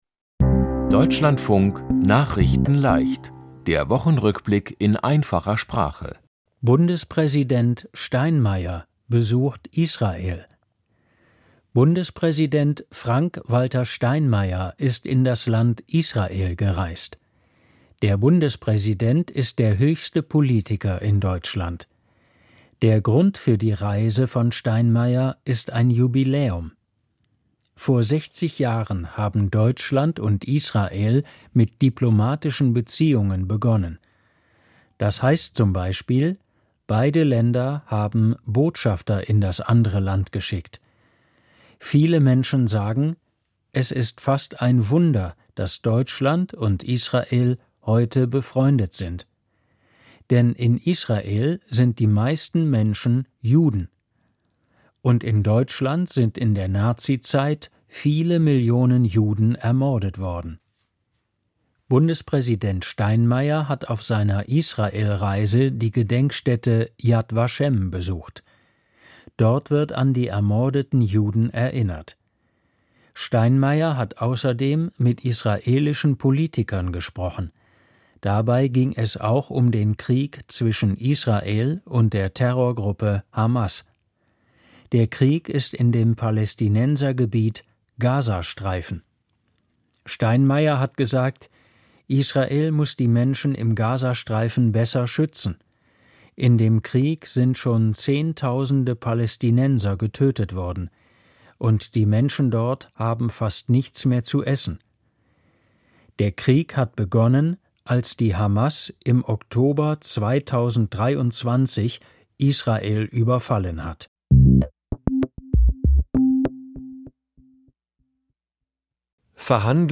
Die Themen diese Woche: Bundes-Präsident Steinmeier besucht Israel, Verhandlungen zwischen Ukraine und Russland, Verein "König-Reich Deutschland" verboten, Margot Friedländer ist tot, Schauspieler Gérard Depardieu verurteilt und Abschied für Fußballer Thomas Müller. nachrichtenleicht - der Wochenrückblick in einfacher Sprache.